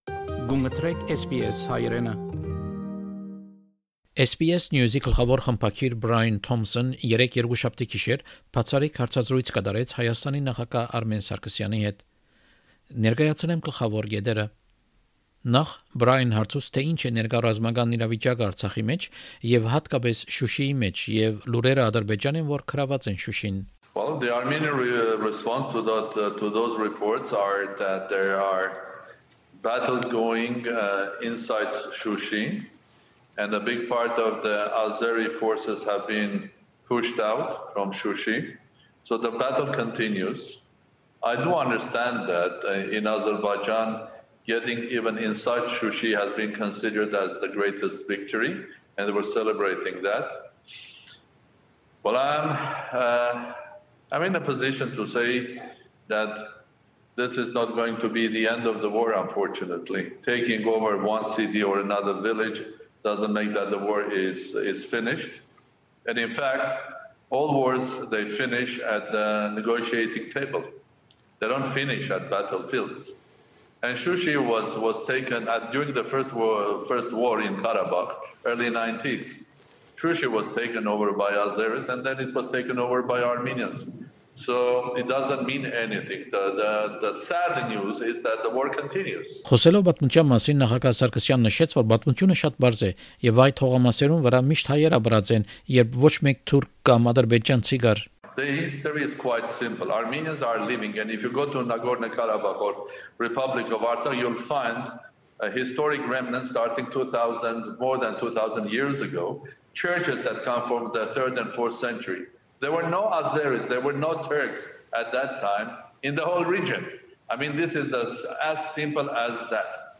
The president also spoke to SBS Armenian. This segment was broadcast in the program tonight and includes highlights of both interviews.